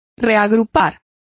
Complete with pronunciation of the translation of regrouped.